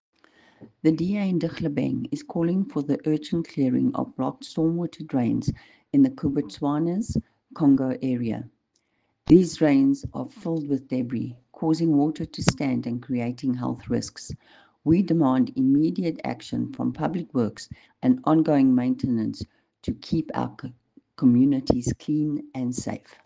Afrikaans soundbites by Cllr Irene Rügheimer and Sesotho soundbite by Cllr Kabelo Moreeng.